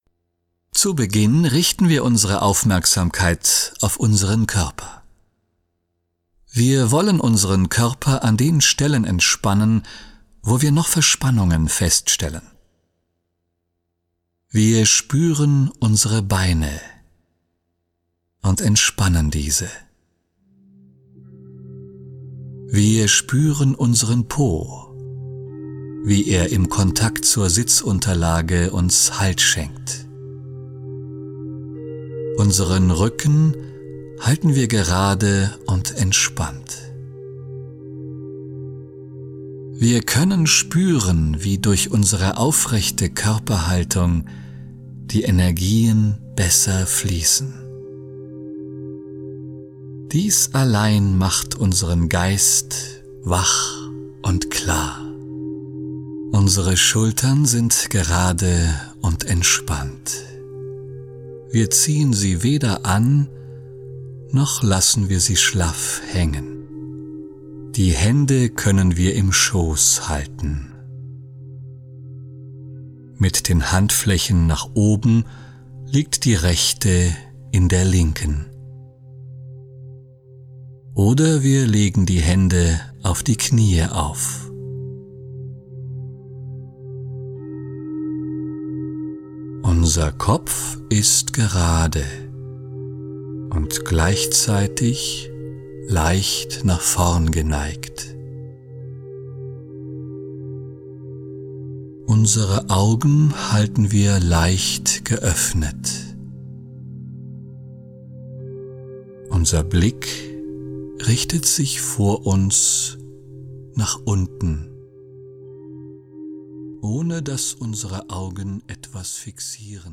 Eine Anleitung für Anfänger …
Der Sprecher wird Sie begleiten, während Sie sich über grundlegende Aspekte unseres Daseins bewusst werden.
Genre: Sonstige Produkte (Wort,Stimmung,…)